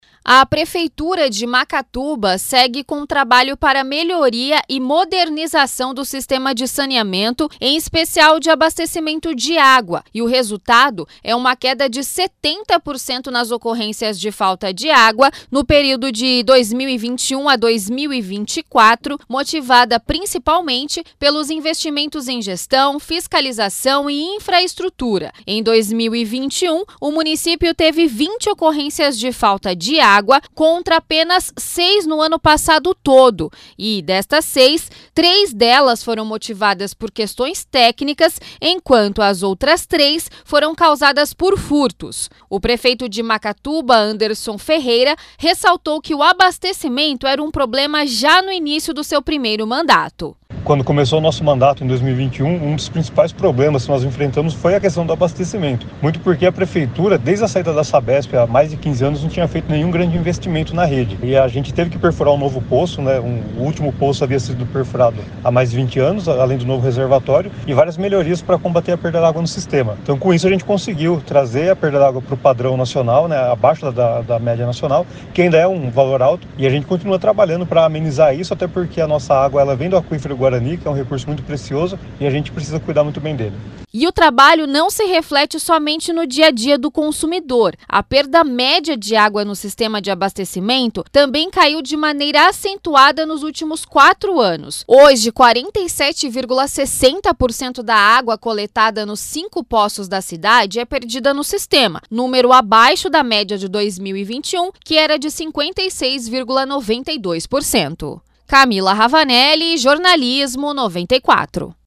Prefeitura de Macatuba reduz falta de água em 70% - 94FM Bauru